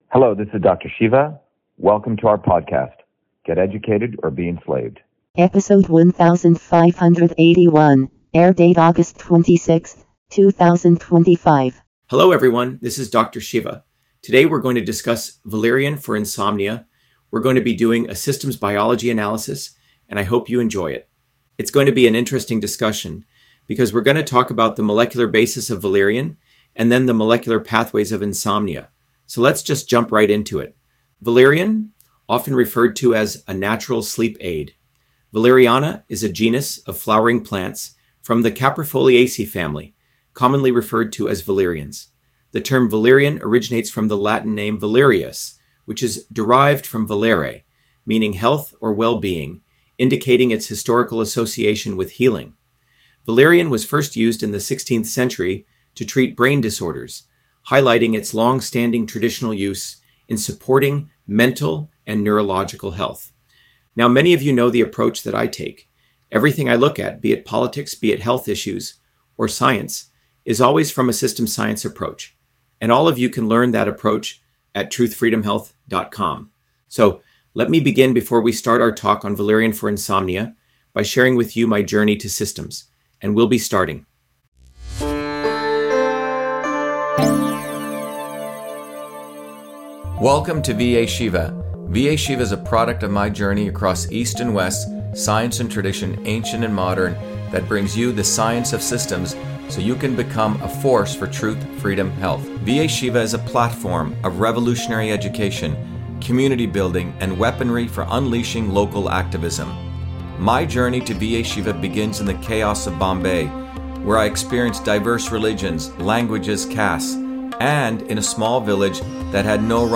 In this interview, Dr.SHIVA Ayyadurai, MIT PhD, Inventor of Email, Scientist, Engineer and Candidate for President, Talks about Valerian on Insomnia: A Whole Systems Approach